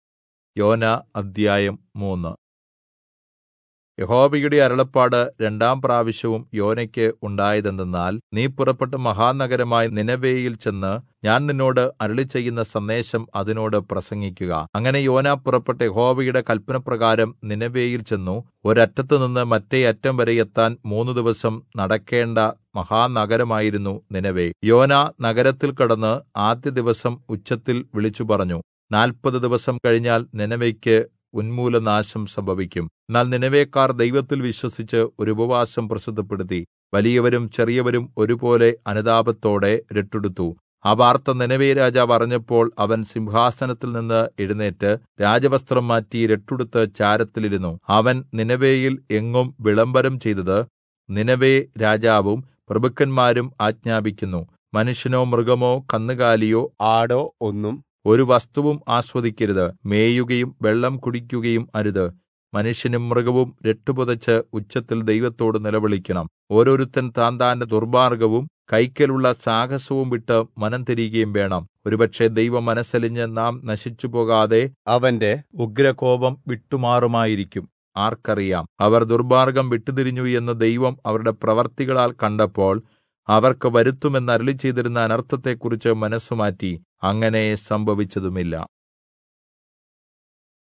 Malayalam Audio Bible - Jonah 4 in Irvml bible version